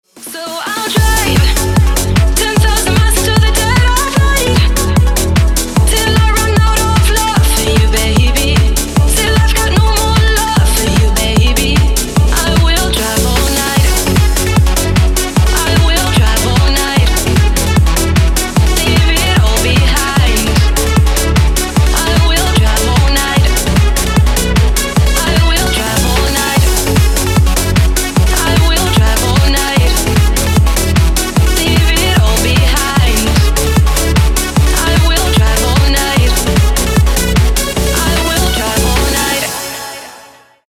Workout Mix Edit 150 bpm